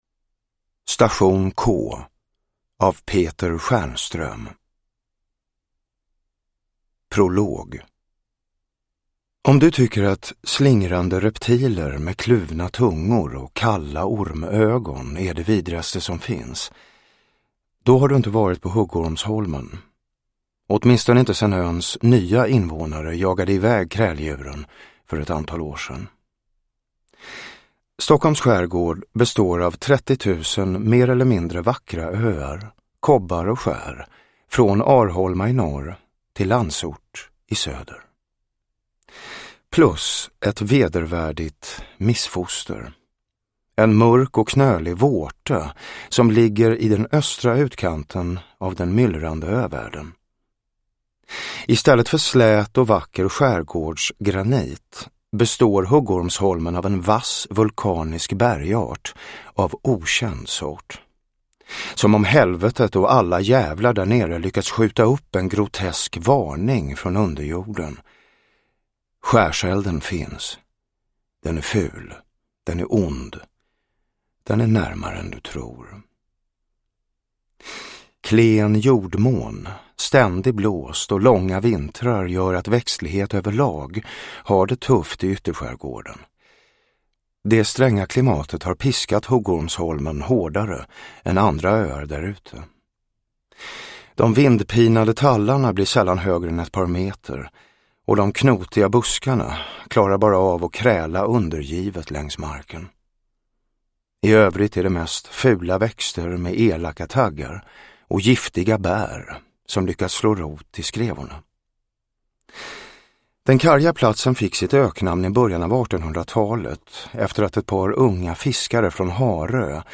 Station K – Ljudbok – Laddas ner
Uppläsare: Jonas Malmsjö